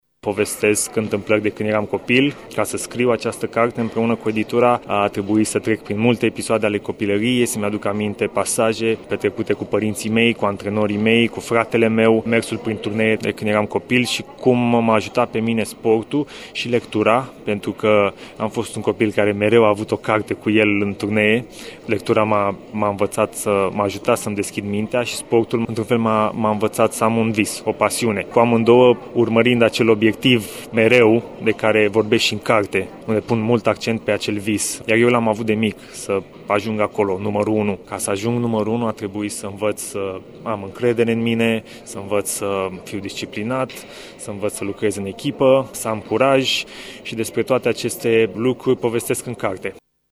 Horia Tecău a declarat pentru Radio Tîrgu-Mureș că această carte l-a dus în copilărie, momentul în care a știut că el trebuie să ajungă campion: